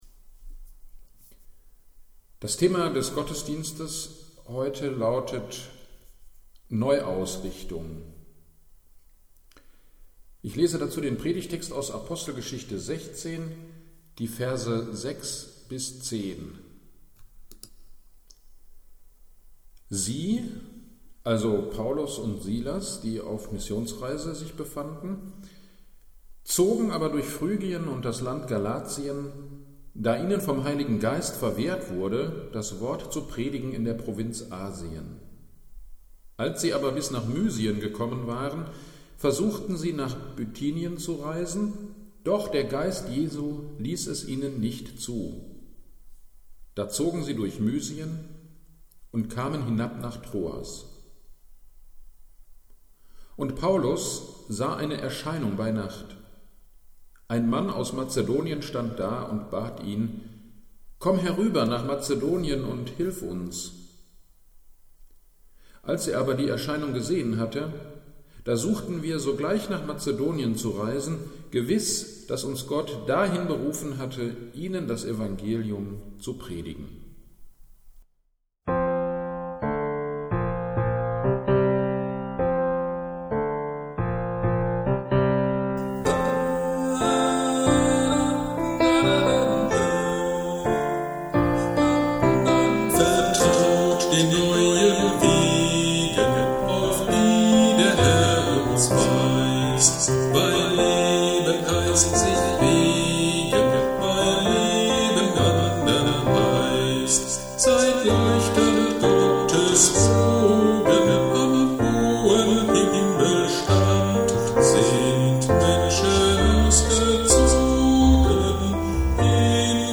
Predigt am 07.02.21 zu Apostelgeschichte 16,6-10